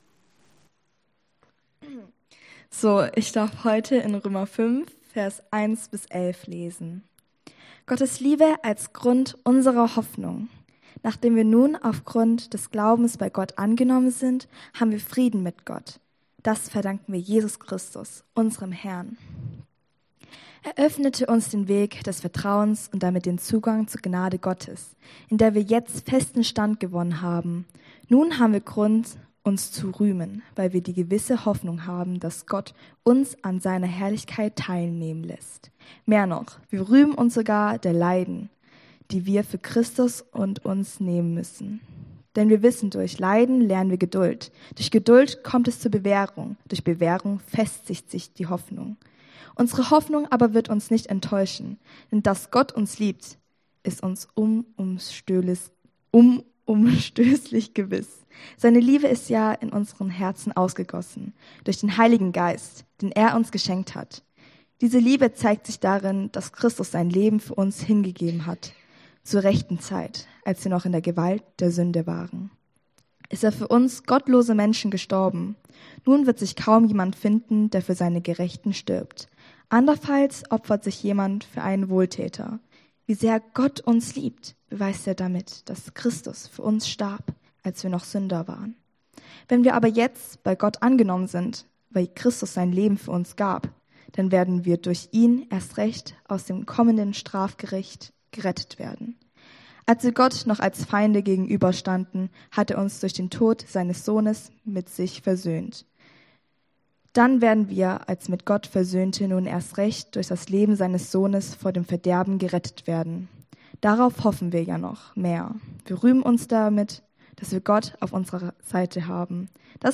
Passage: Römer 5 Dienstart: Gottesdienst